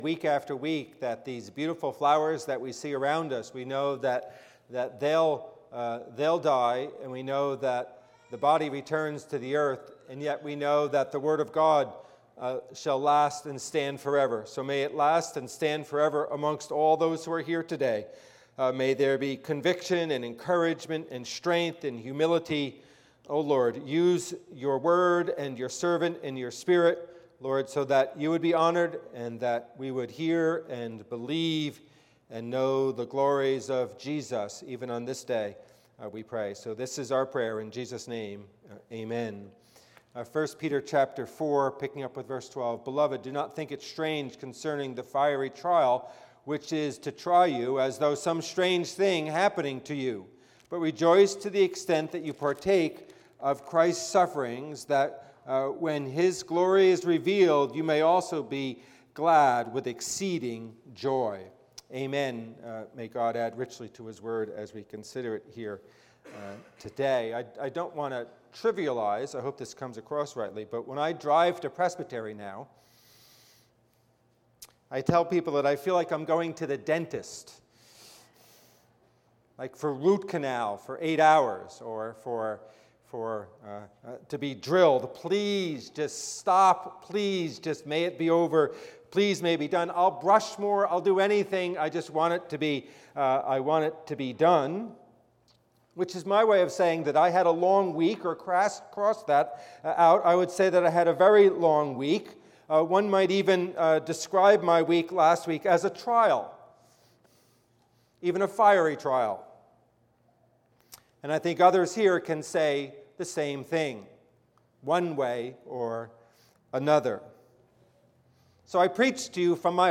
Passage: 1 Peter 4:12-13 Service Type: Worship Service « Forgiveness